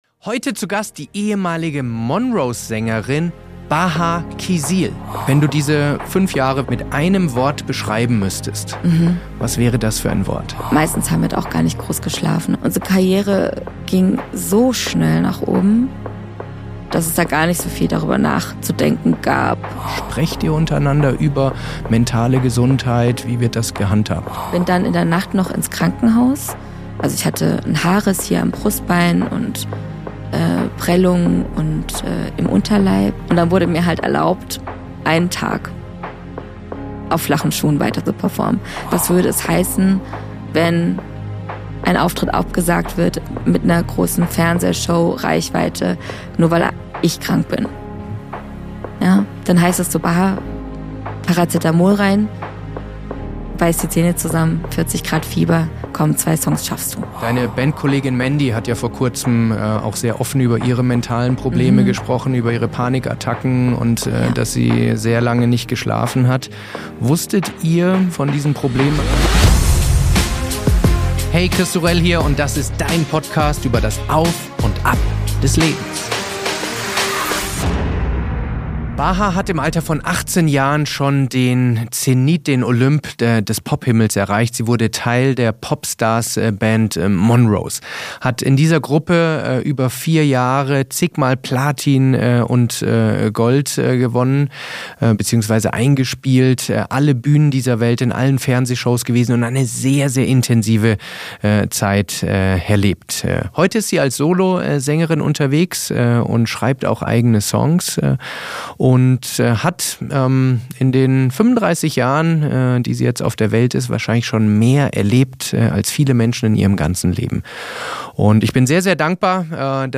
Dieses Gespräch ist auf ALLEN Plattformen viral gegangen - wie ich finde zu recht.